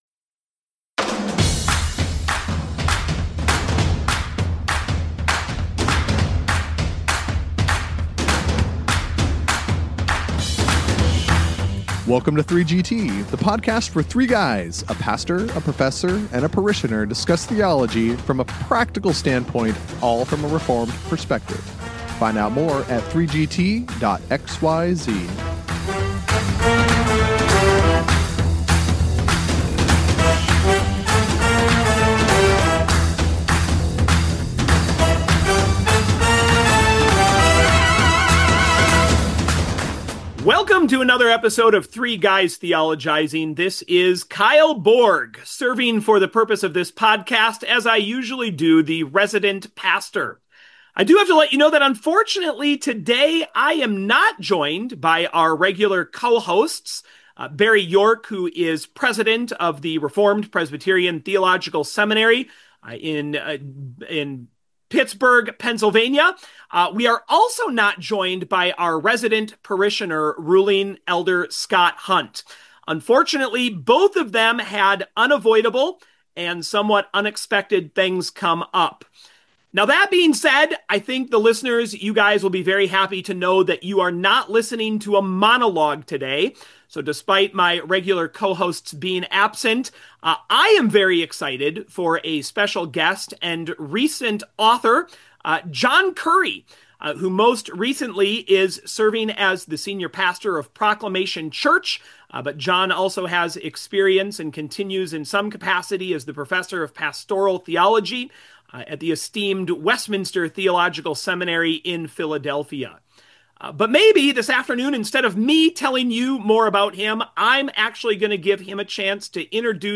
Two guys theologize this week on the topic of biblical leadership in the pastorate.
Do you want to hear a refreshing, helpful, and (most importantly!) faithful conversation on pastoral leadership?